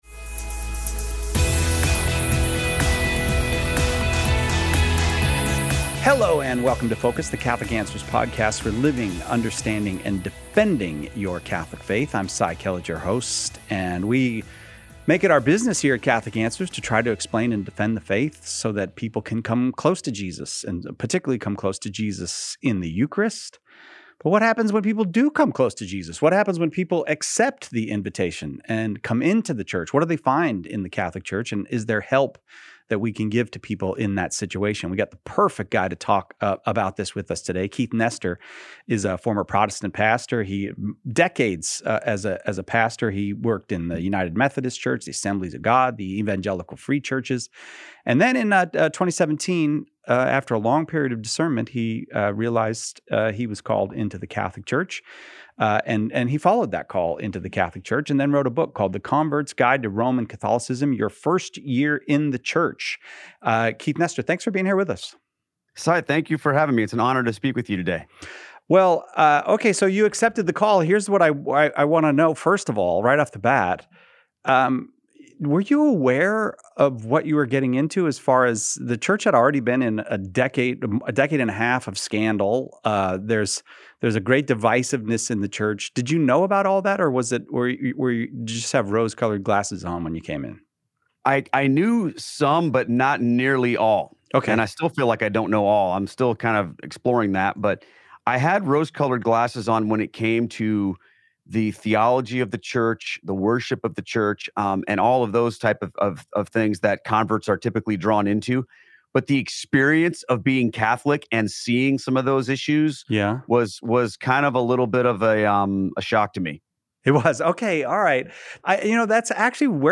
a former Protestant pastor who converted to Catholicism. They discuss the challenges faced by converts and how to help them navigate their first year in the Catholic Church.